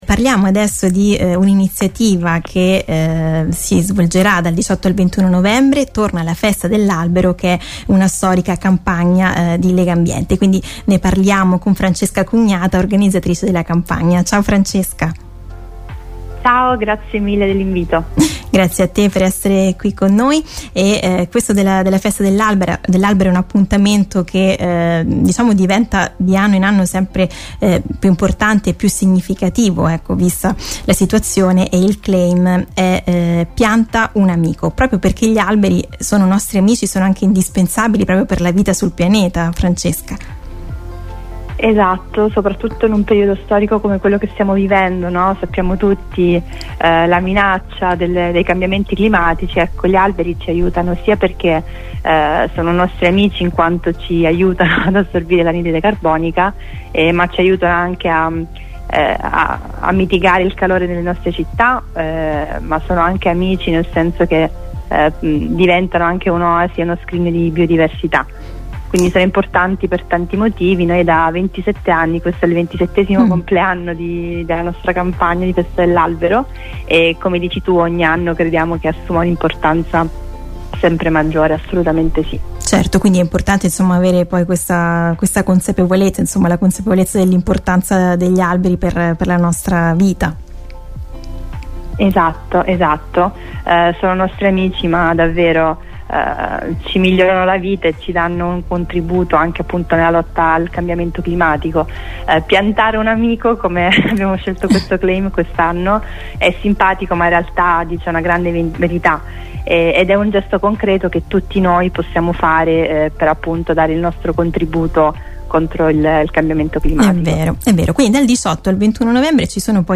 ne ha parlato con